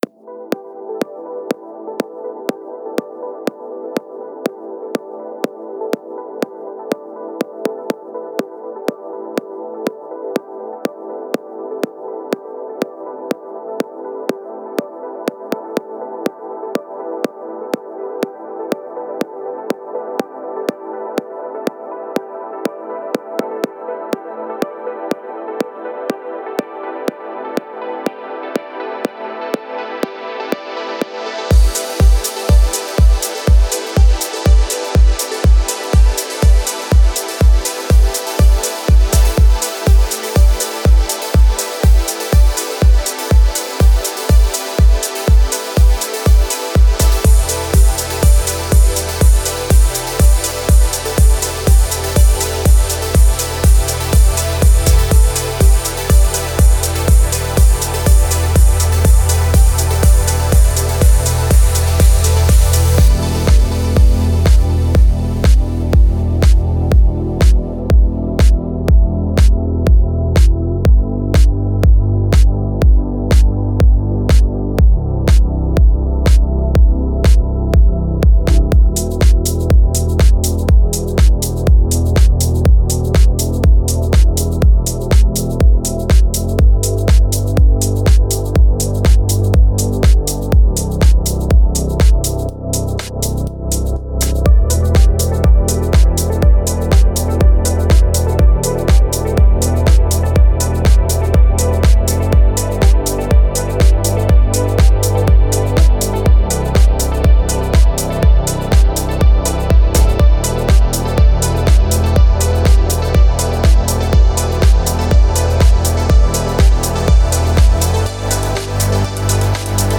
Стиль: Progressive House